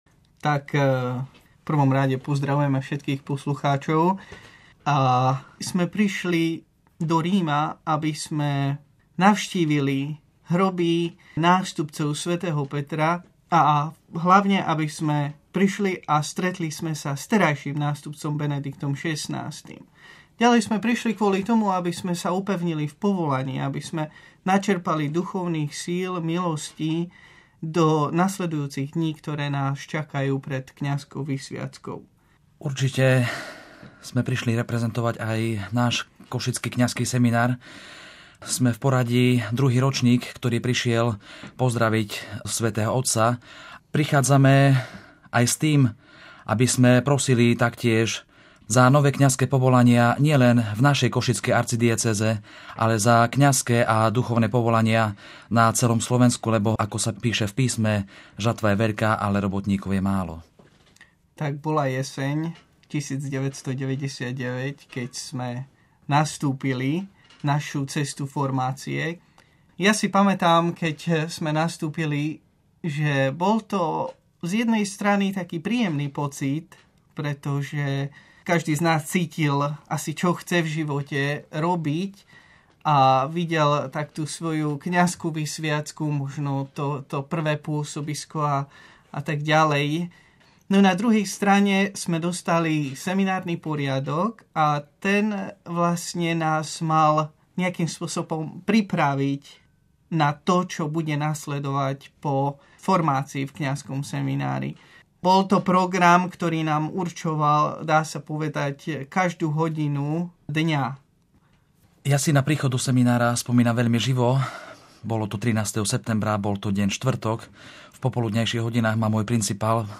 Rozhovor týždňa: život v seminári